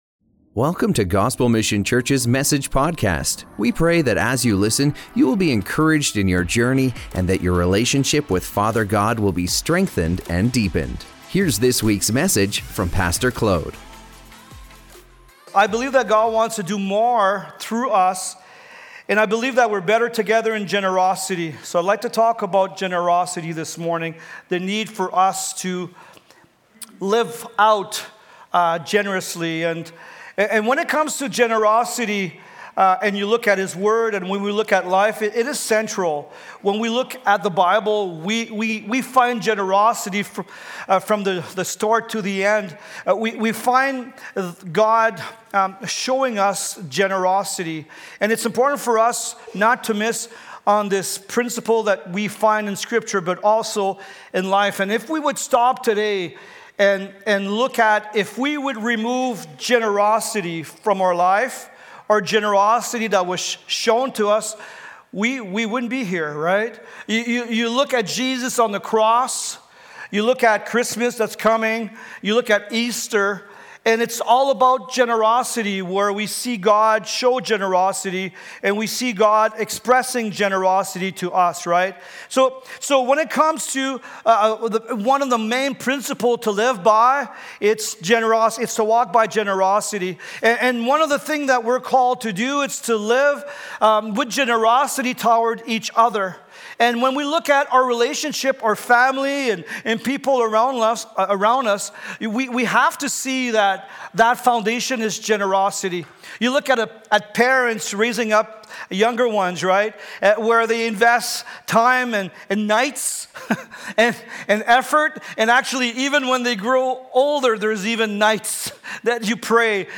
Better Together Generosity and God’s Kingdom are linked! In this sermon